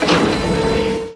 mineralscoop.ogg